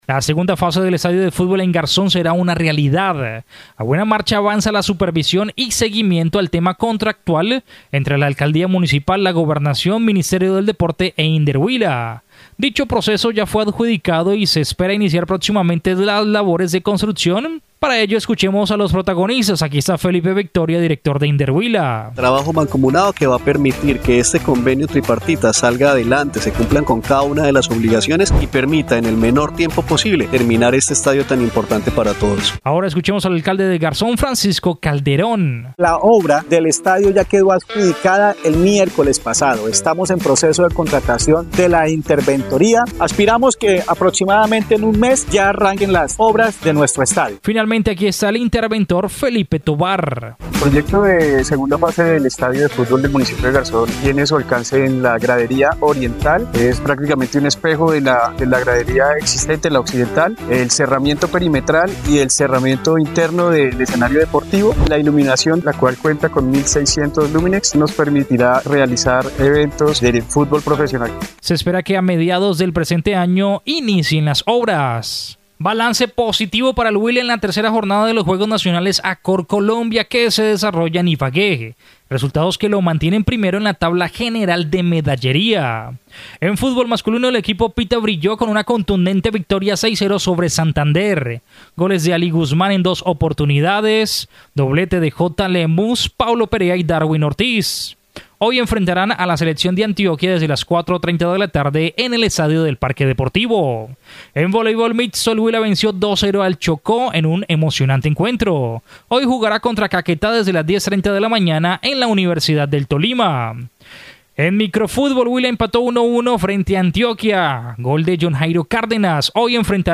Dicho proceso ya fue adjudicado y se espera iniciar proximamente las labores de contrucción, escuchemos a los protagonistas, aquí está Felipe Victoria director de Inderhuila.